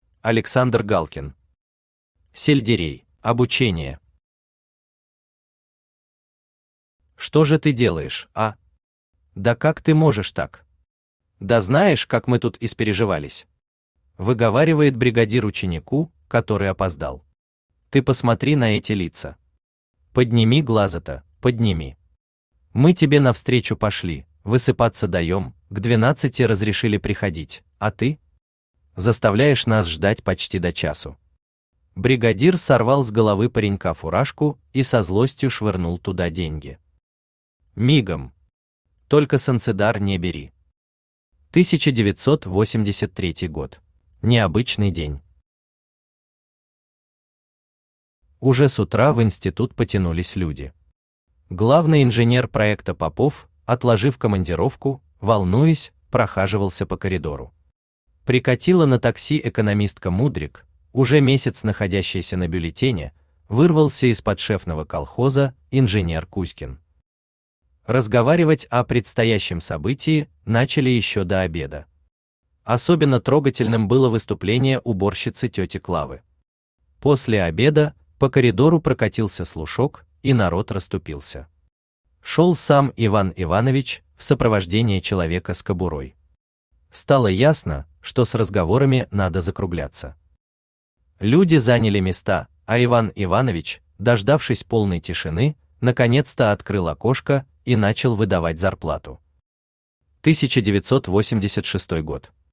Аудиокнига Сельдерей | Библиотека аудиокниг
Aудиокнига Сельдерей Автор Александр Николаевич Галкин Читает аудиокнигу Авточтец ЛитРес.